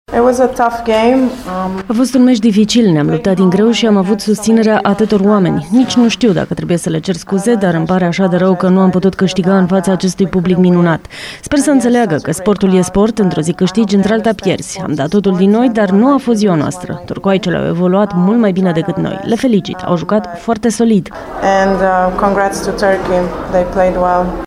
declaraţia